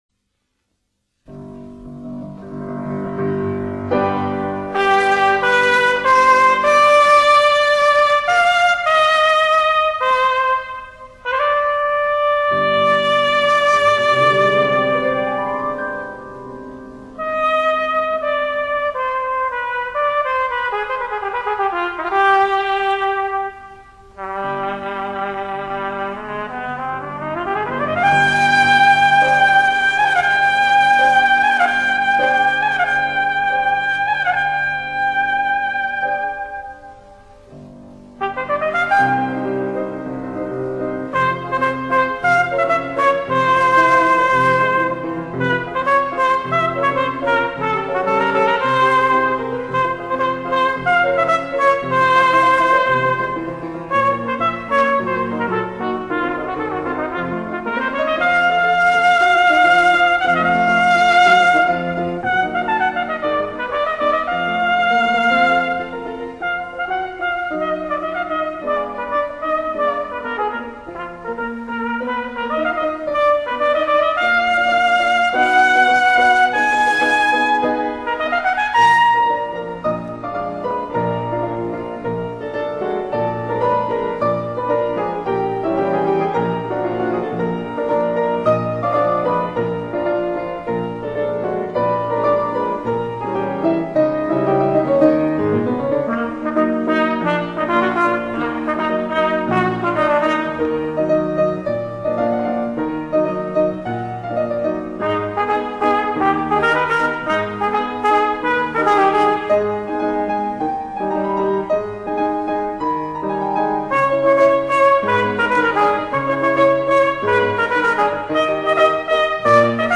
钢琴